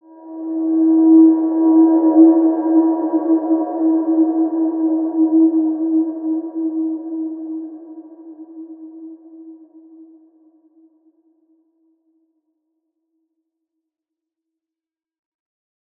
Large-Space-E4-p.wav